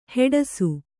♪ heḍasu